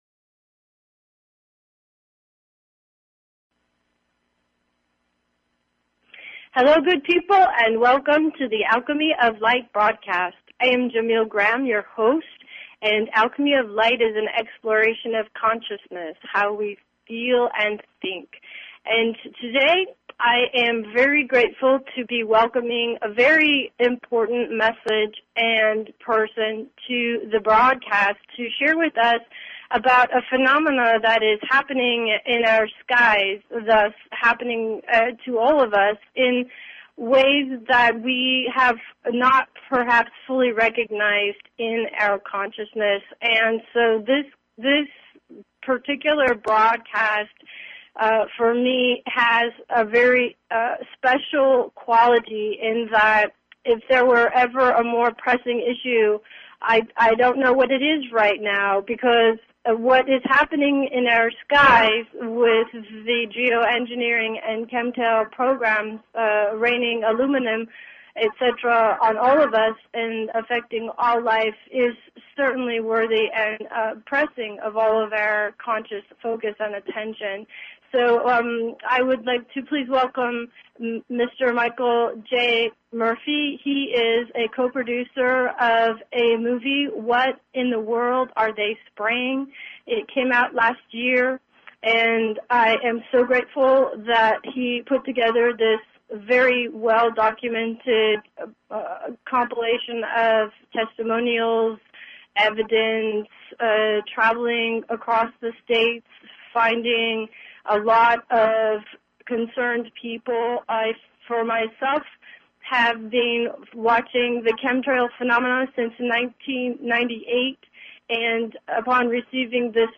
The Alchemy of Light Broadcast is a celebration and exploration of Consciousness. This show integrates the available progressive social and natural science based genius alongside the more intimate genius of individuals sharing their stories.